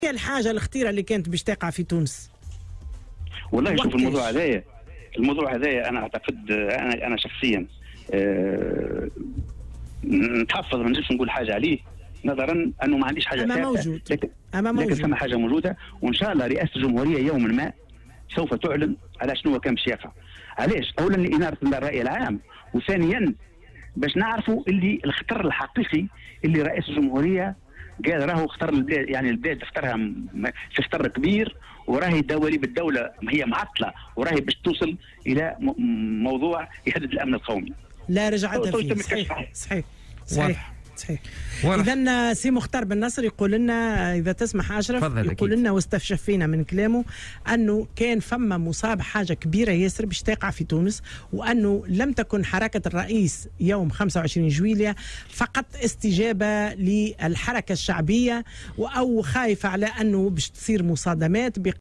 وأضاف في مداخلة له اليوم في برنامج "بوليتيكا" أنه يتحفظ عن الحديث في هذه المسألة، مشيرا إلى ان رئاسة الجمهورية ستكشف في يوم ما عن ما كان سيحصل وستنير الرأي العام.